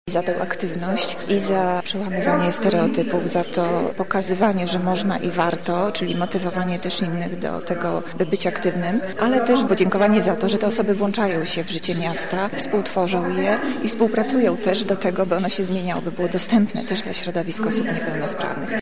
Okazją była Gala „Aktywni z Lublina”. Jak mówiła w rozmowie z dziennikarzami zastępca prezydenta Monika Lipińska – „jest za co dziękować”.